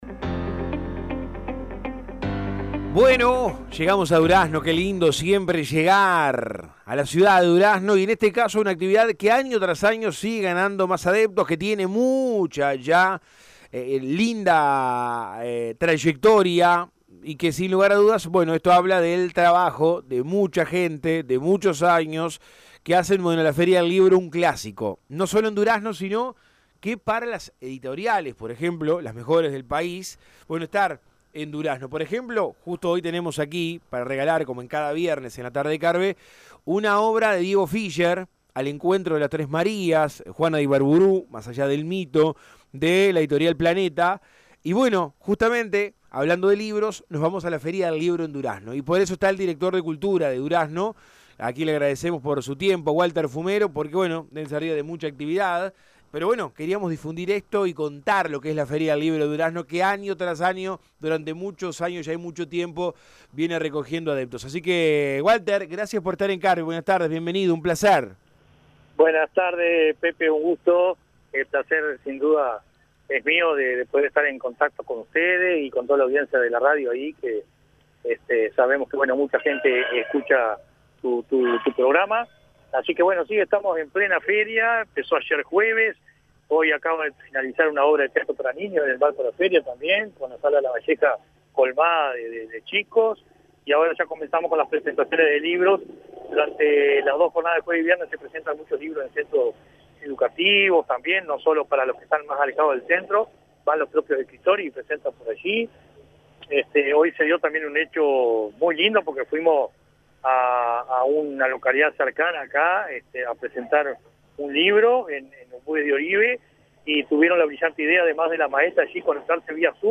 La Feria del Libro de Durazno comenzó el jueves 7 de noviembre y se extiende hasta el domingo 10, ofrece una variada programación que incluye exposición y venta de libros, stands de librerías locales y nacionales, exposiciones institucionales, así como espectáculos artísticos y musicales. Walter Fumero director de Cultura dialogó con Todo Un País donde dio detalles de las actividades que se están llevando a cabo en el marco de la feria del libro.